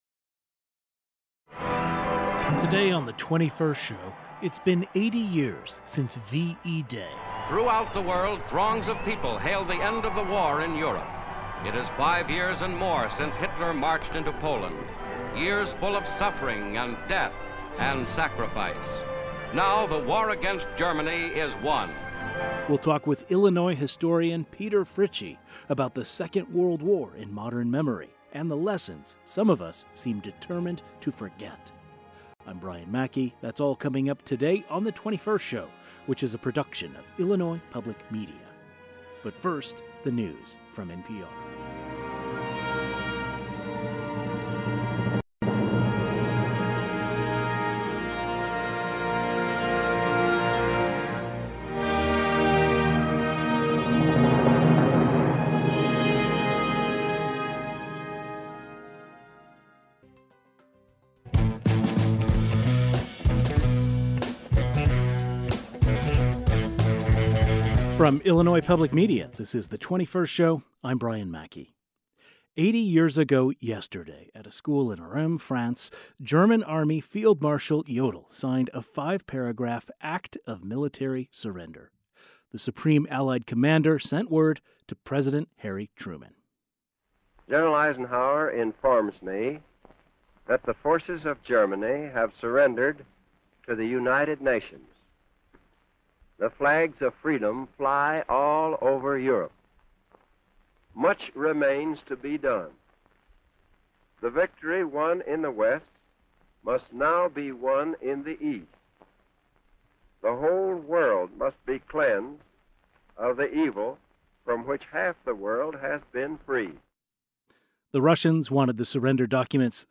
A historian and author who has written about World War II joins the program.